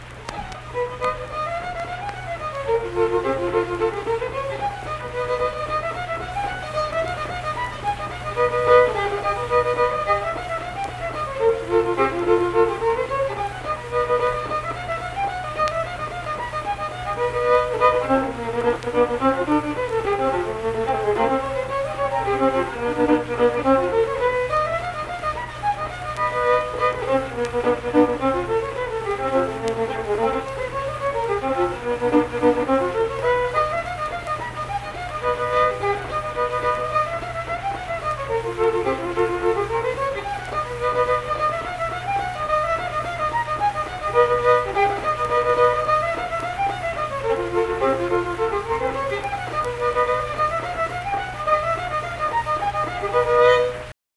Unaccompanied fiddle music
Instrumental Music
Fiddle
Saint Marys (W. Va.), Pleasants County (W. Va.)